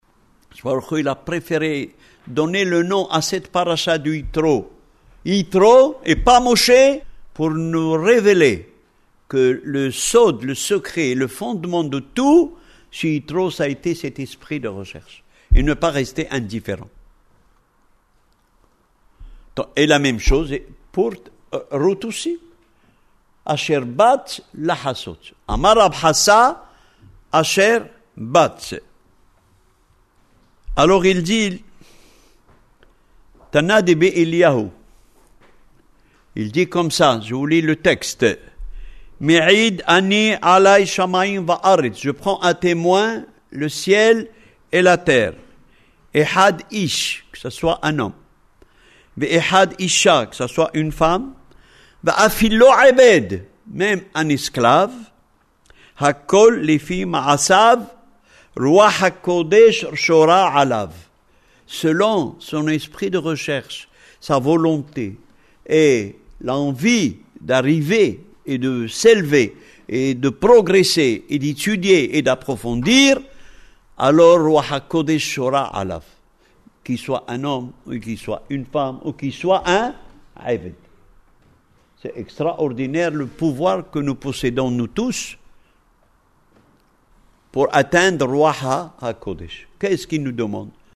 Le thème traité ce soir est l’indifférence et l’esprit de recherche. Nous étions à la grande synagogue de Maisons Alfort le Motsé Shabbath 04 Kislev 5767 – 25 novembre 2006. L’indifférence est le trait caractéristique du comportement de Essav, qui vend sa Bekhora, son droit d’ainesse, contre un plat de lentilles.